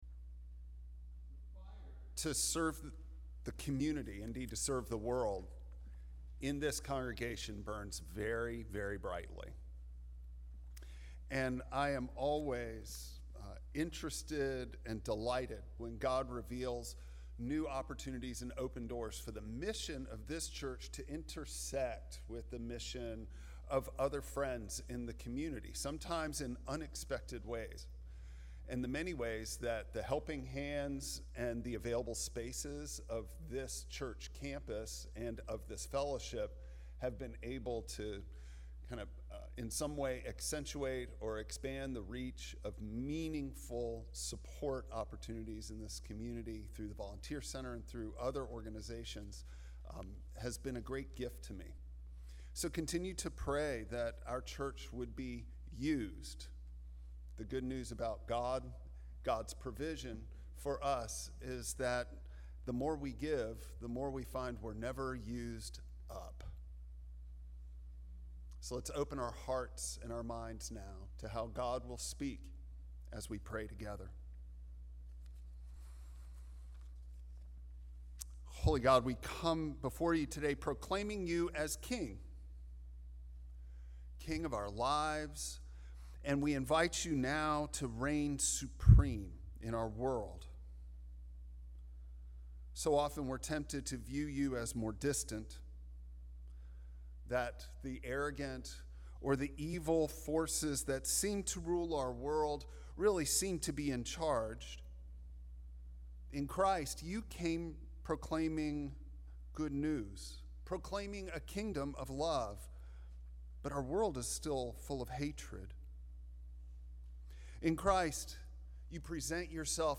Passage: John 18:33-37 Service Type: Traditional Service Bible Text